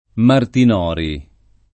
[ martin 0 ri ]